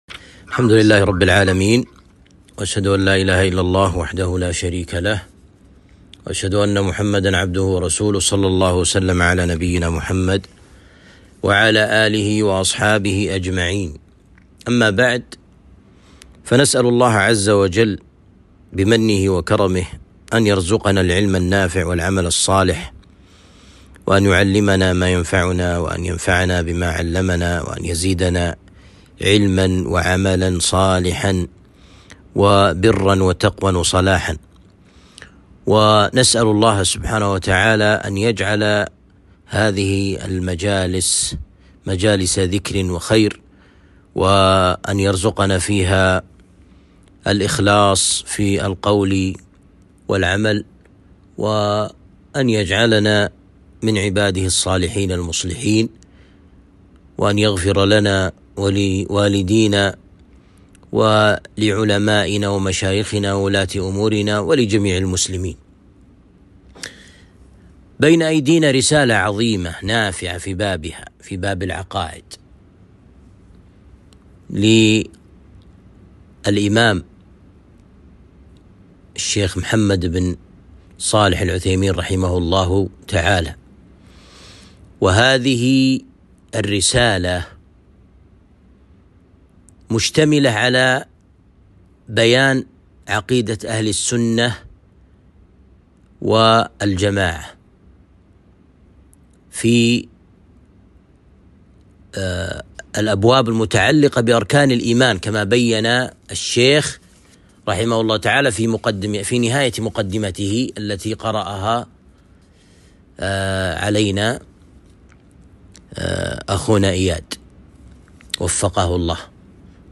الدرس الأول - شرح عقيدة اهل السنة والجماعة - الشيخ ابن عثيمين